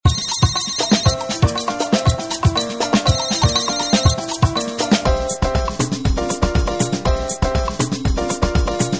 Thể loại nhạc chuông: Nhạc chuông iPhone